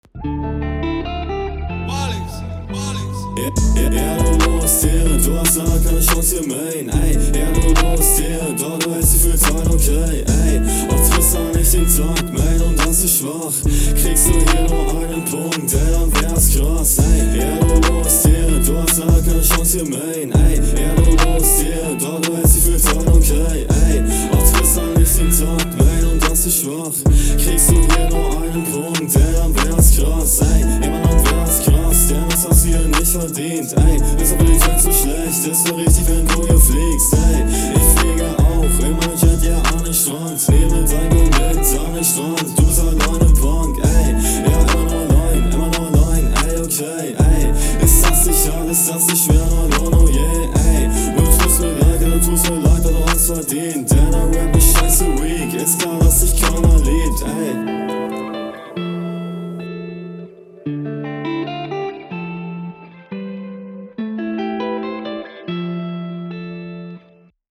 Lautstärke/Verständlichkeit zieht sich durch alle 3 Runden.